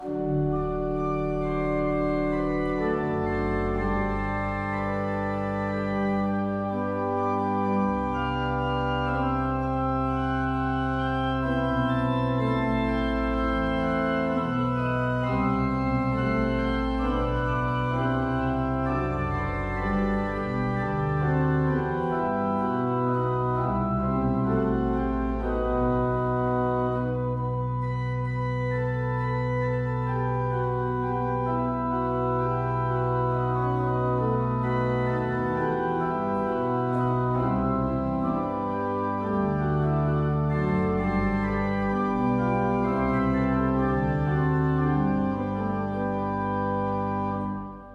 kostel sv. Jana Nepomuckého
Nahrávky varhan:
Vsemina, Principal 8, Oktava 4, Flauta 2.mp3